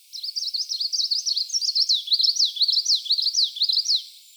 tp 10 : evolution genomes     ECE chant des oiseaux
chant Plumbeitarsus